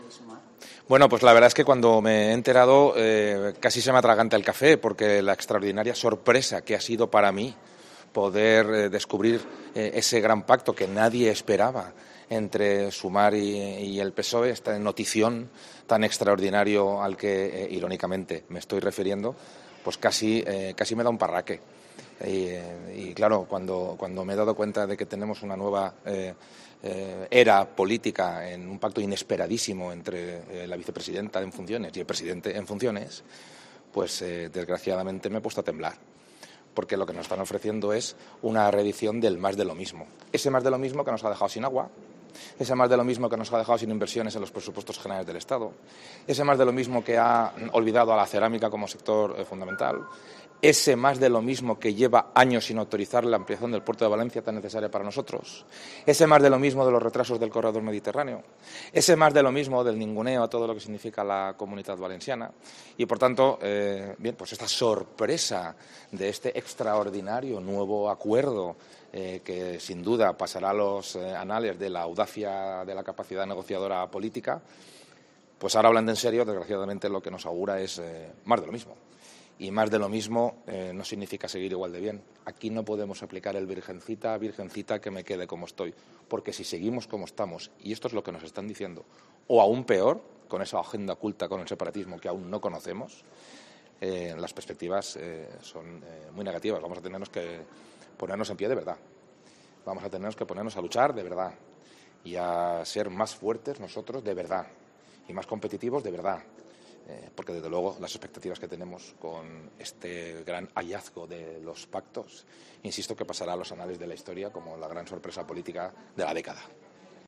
Así se ha pronunciado, a preguntas de los medios este martes, en su asistencia a la jornada IIICharIN Europe Conference organizada por Power Electronics en Llíria (Valencia), después de que el PSOE y Sumar hayan alcanzado un acuerdo programático para formar un nuevo Gobierno de coalición tras cerrar sus líderes, Pedro Sánchez y Yolanda Díaz, los detalles del pacto.